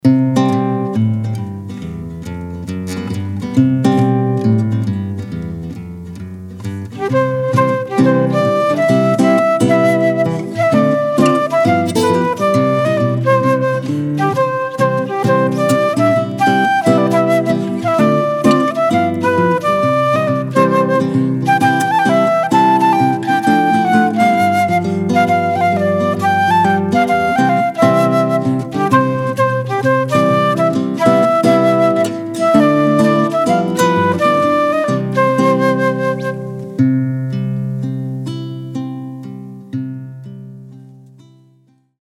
A selection of traditional Christmas music
guitarist